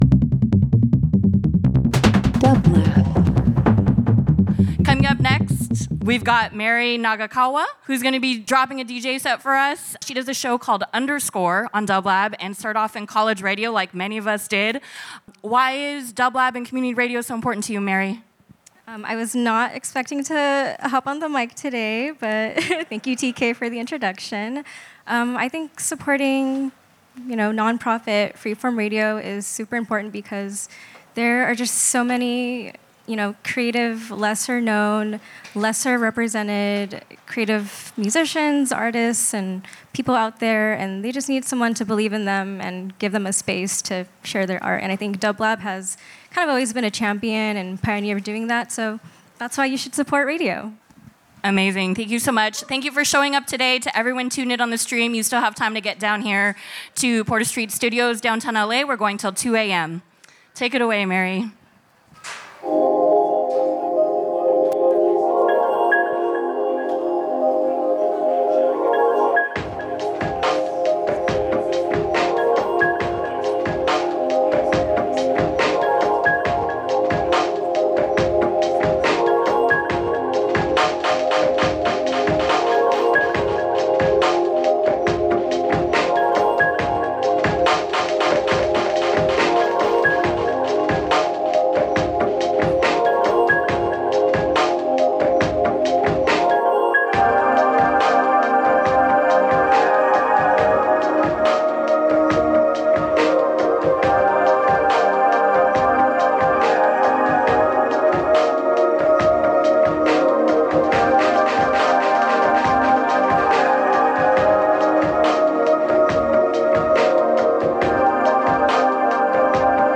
[LIVE FROM THE LOFT @ PORTER STREET STUDIO – OCT 11, 2025]
Downtempo Dream Pop Instrumental Jazz Soul